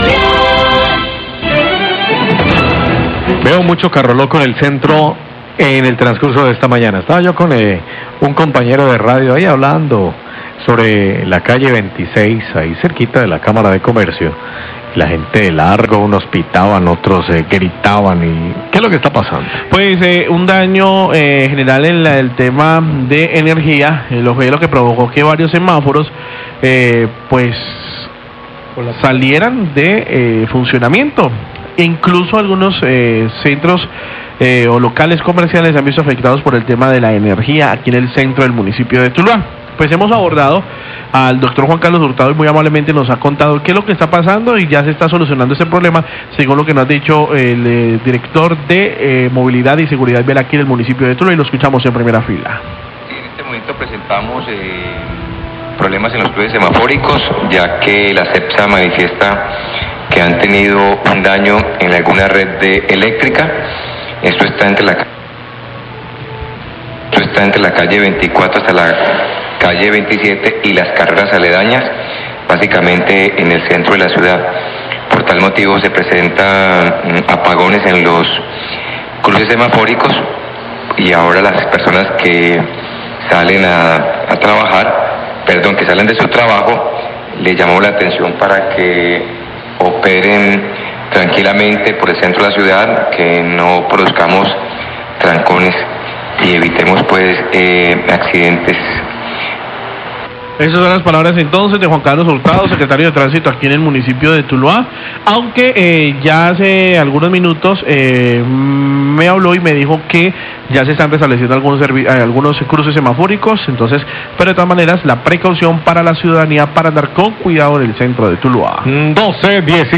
Radio
Daños eléctricos en el centro de la ciudad, calle 24 con carrera 27 ocasionan fallos en el servicio semafórico, el director de movilidad Juan Carlos Hurtado explica lo sucedido y le pide a la comunidad tener cuidado al transitar por esta zona, la empresa de energía trabaja en este momento para solucionar el problema.